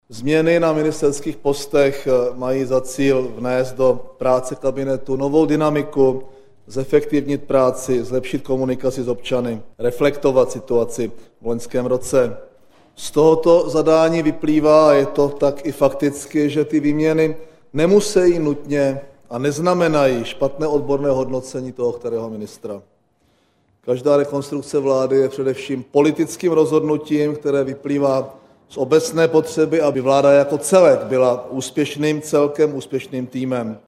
Premiér M. Topolánek k výměně ministrů